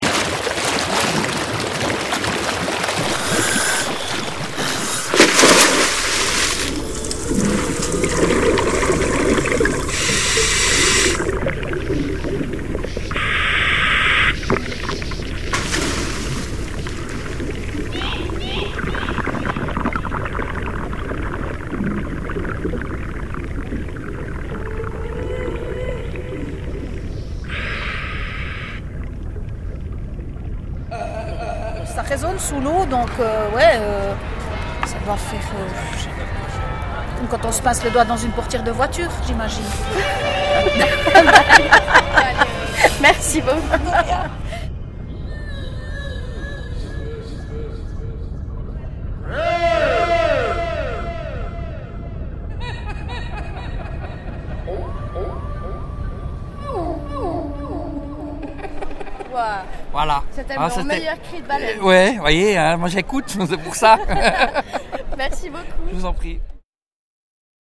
Pour les téméraires, il y a notre remix-compil cris de baleines ultra bien faits par nos très chers festivaliers.